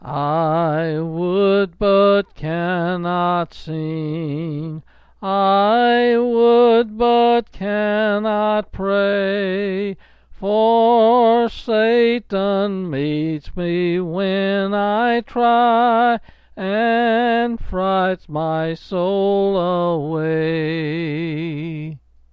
Quill Pin Selected Hymn
S. M.